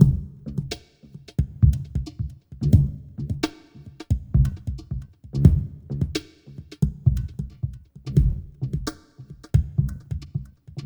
TRIP-HOPPI-L.wav